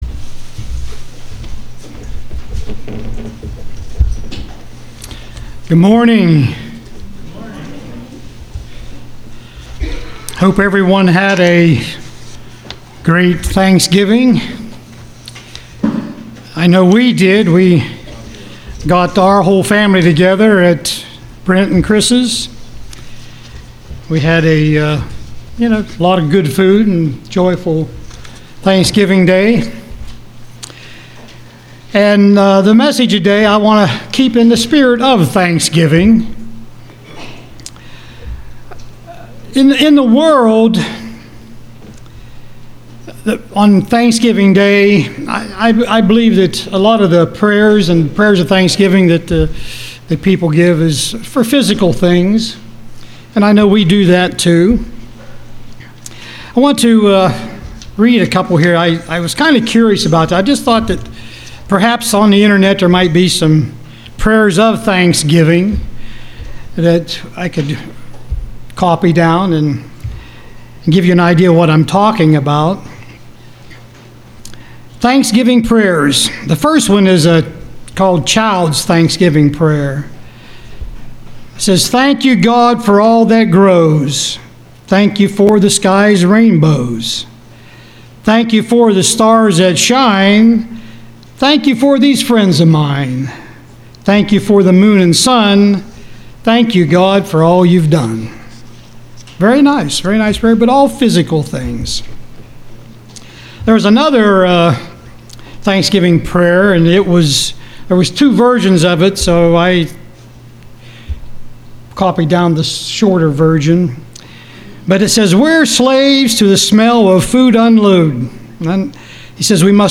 Print Spiritual Blessings UCG Sermon